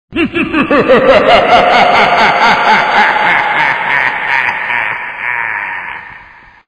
TestLaugh_Float32.wav